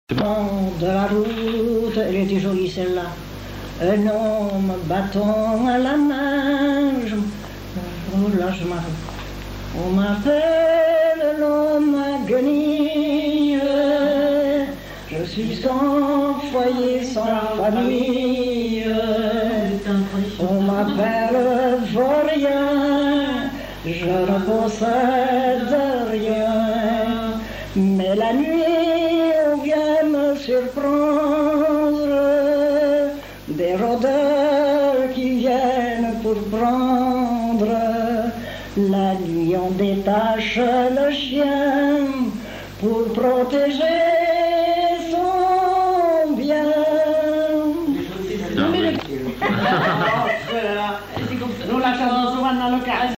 Lieu : Mont-de-Marsan
Genre : chant
Type de voix : voix de femme
Production du son : chanté